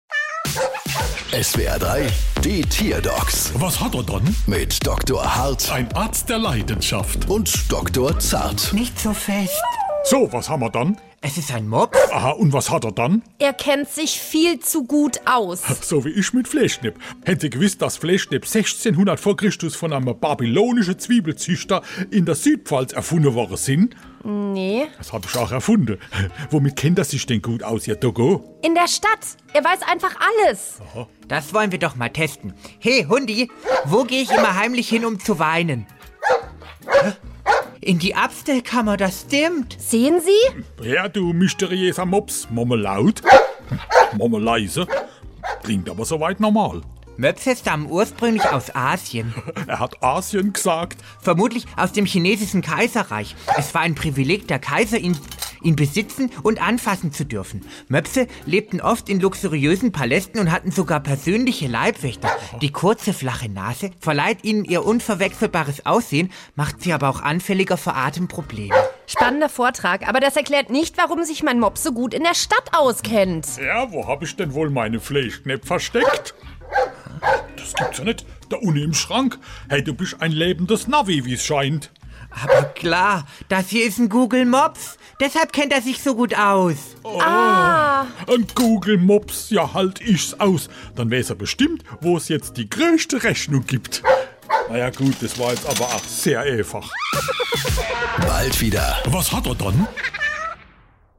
SWR3 Comedy Die Tierdocs: Mops kennt sich aus